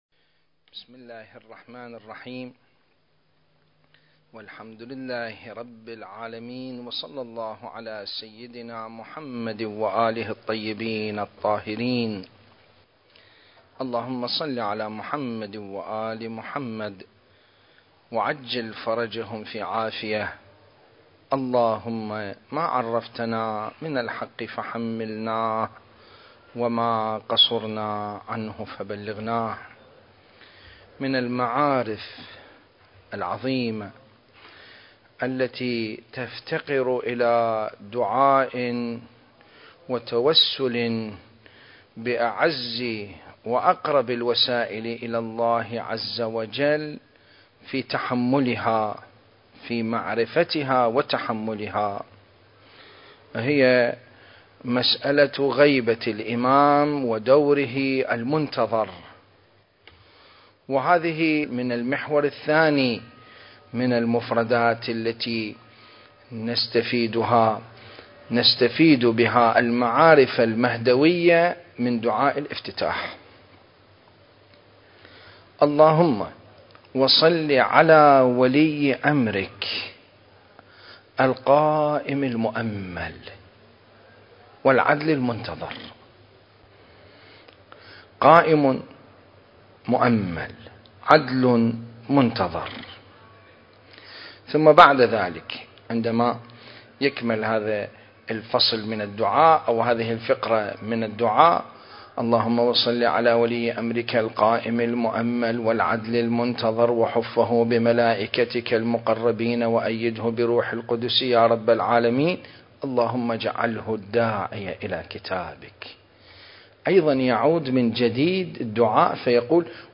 سلسلة: الإمام المهدي (عجّل الله فرجه) في دعاء الافتتاح (5) المكان: العتبة العلوية المقدسة التاريخ: 2021